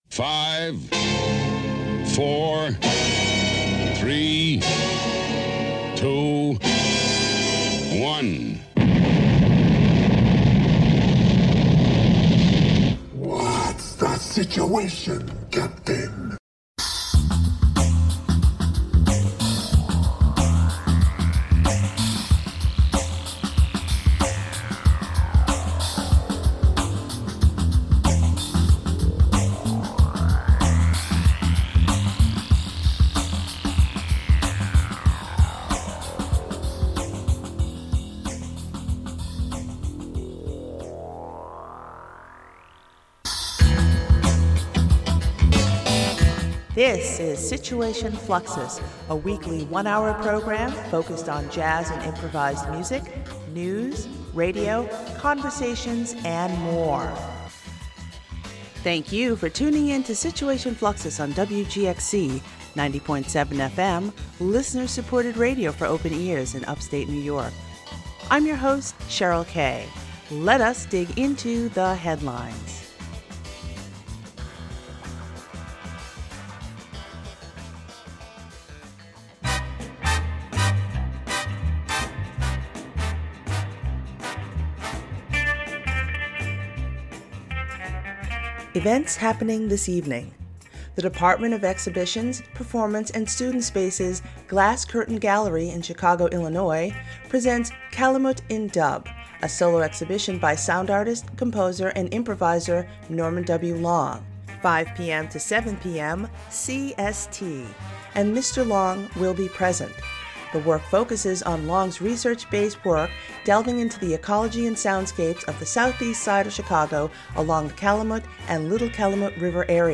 In this broadcast, an interview with pianist, composer, vocalist, and educator
A weekly one-hour program focused on jazz and improvised music, news, radio, conversations, and more.